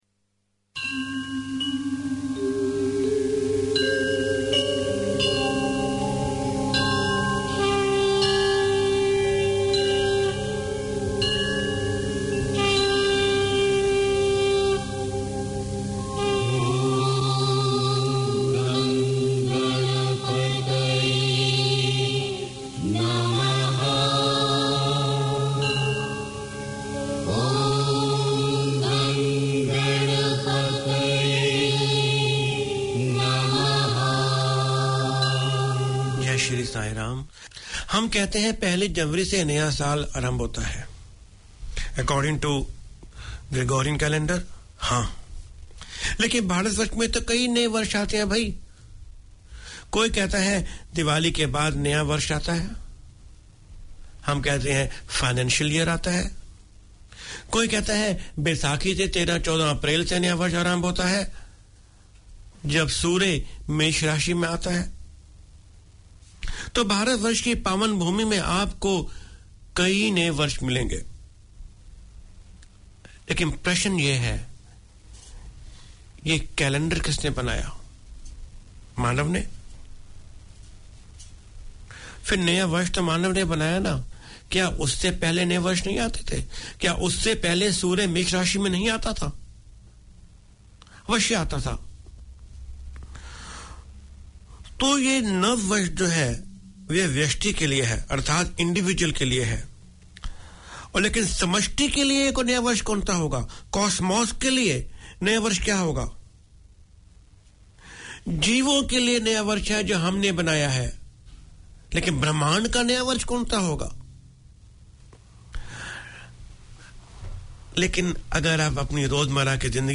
Community Access Radio in your language - available for download five minutes after broadcast.
The programme showcases the history, traditions and festivals of India and Fiji through storytelling and music, including rare Fiji Indian songs.